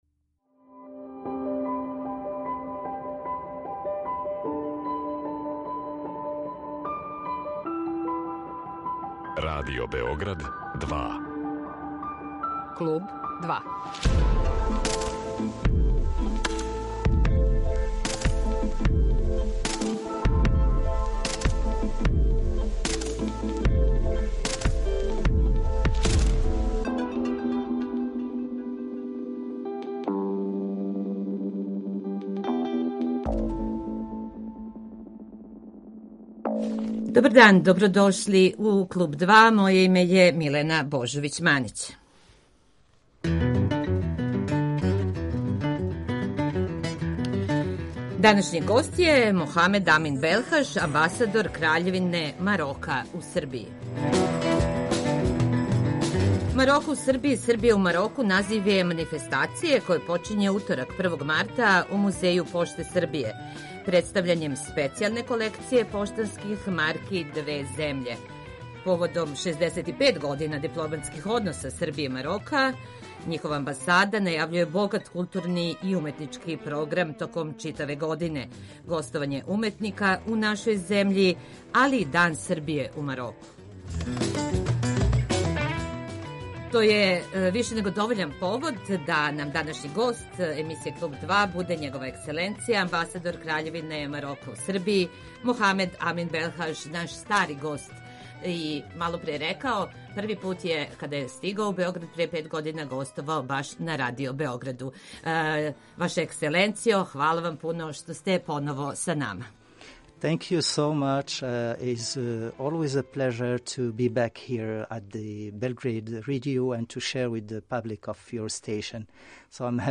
Гост емисије је амбасадор краљевине Марока у Србији Мохамед Амин Белхаж